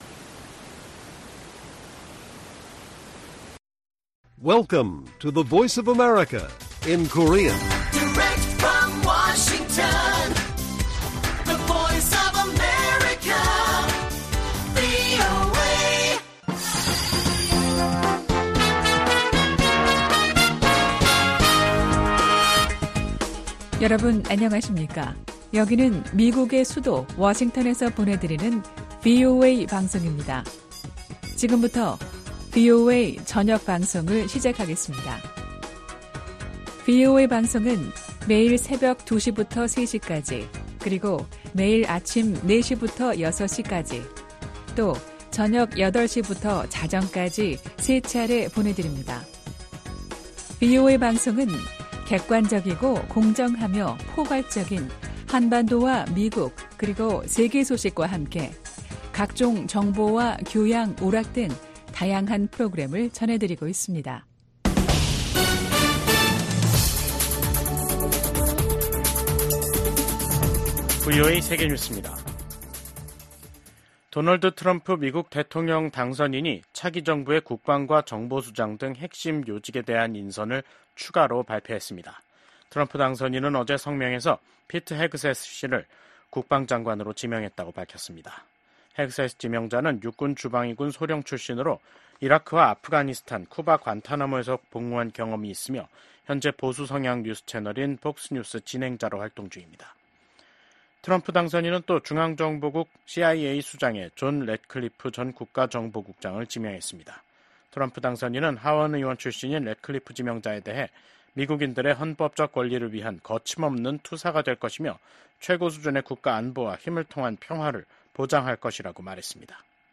VOA 한국어 간판 뉴스 프로그램 '뉴스 투데이', 2024년 11월 13일 1부 방송입니다. 미국 백악관이 북한군의 러시아 파병을 공식 확인했습니다. 최소 3천명이 러시아 동부 전선에 파병됐으며 훈련 뒤엔 우크라이나와의 전투에 배치될 가능성이 있다고 밝혔습니다.